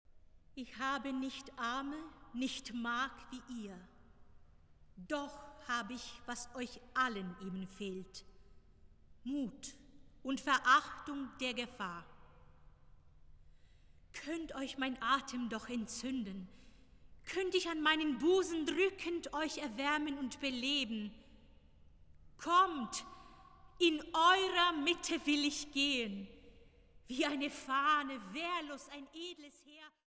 Oboe
Trompete